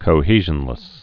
(kō-hēzhən-lĭs)